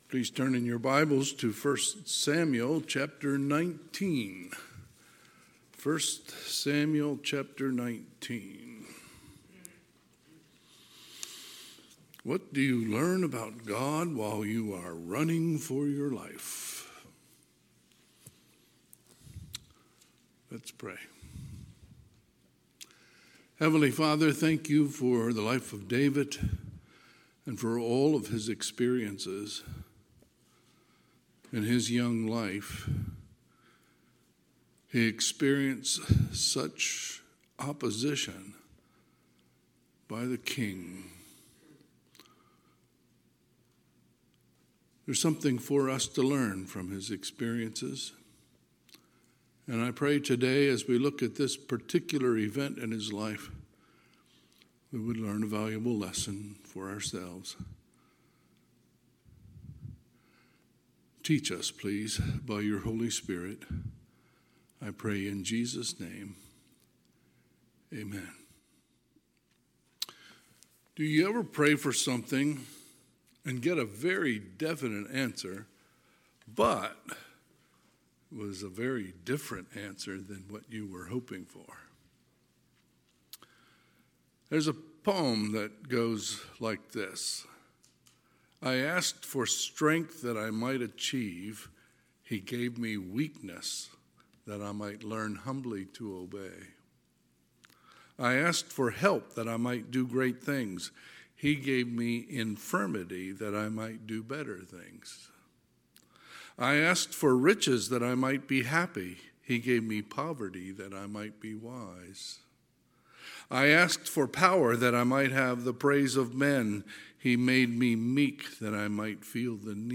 Sunday, March 2, 2025 – Sunday AM